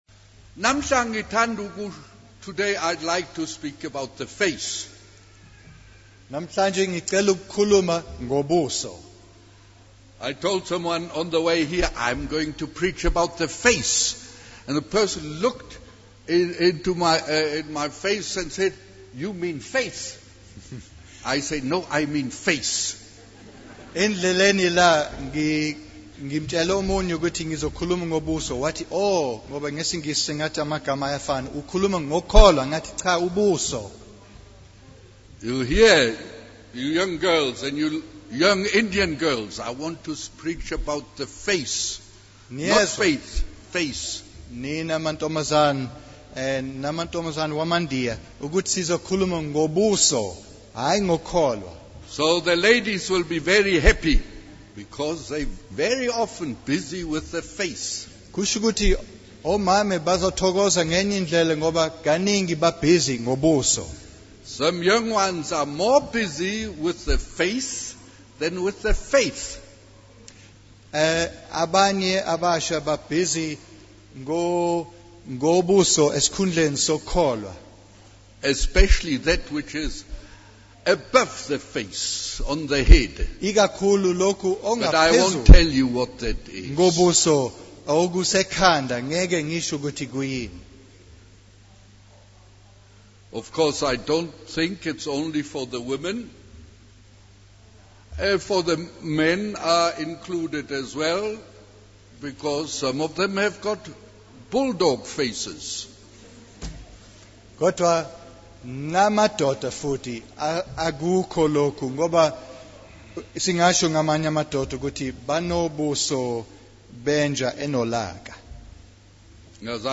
In this sermon, the preacher emphasizes the importance of the face in reflecting one's faith. He begins by reading from Matthew 17:2, where Jesus' face shines with glory on the mount of transfiguration.